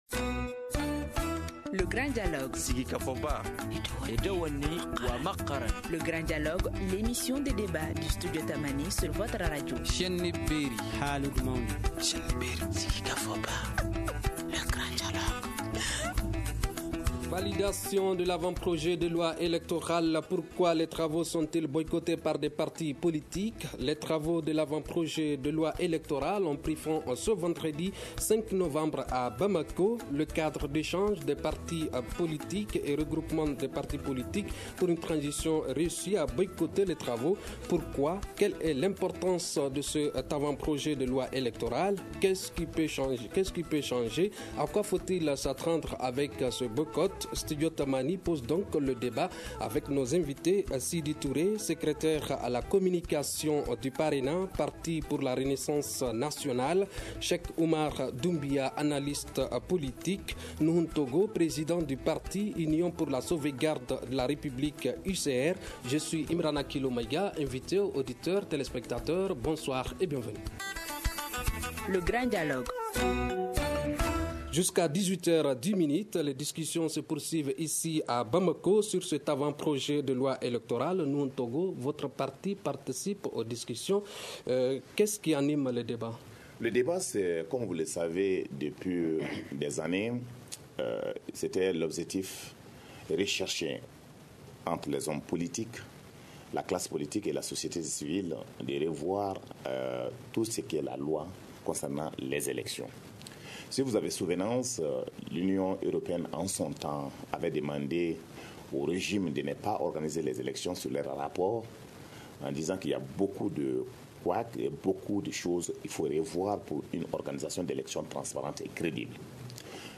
Studio Tamani pose le débat.